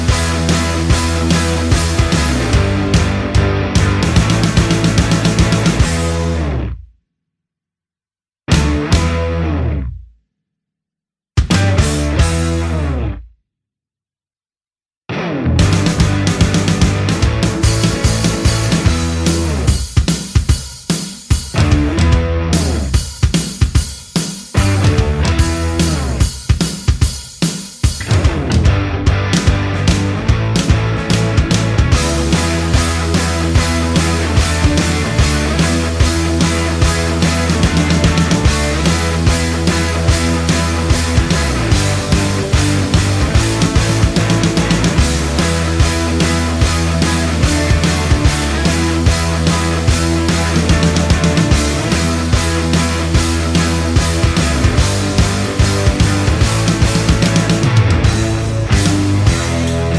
Pop (2020s)